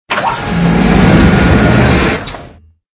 CHQ_VP_ramp_slide.ogg